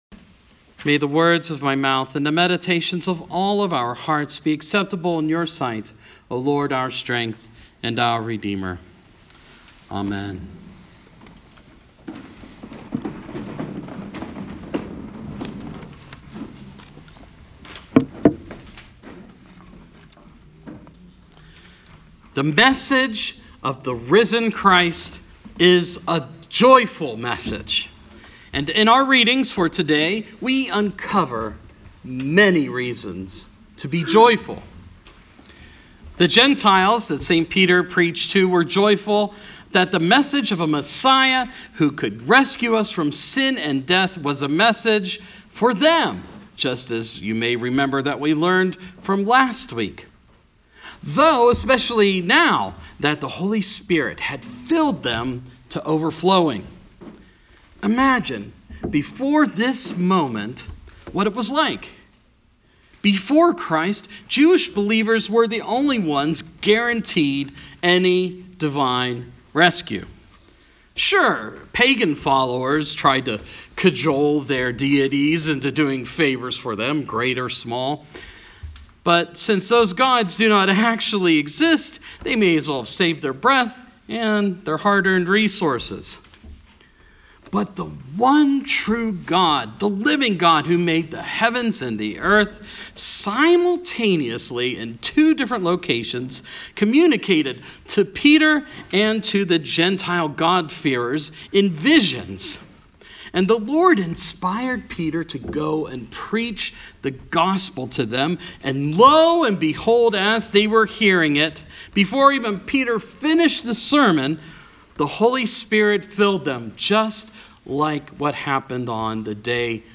Sermon – Sixth Sunday of Easter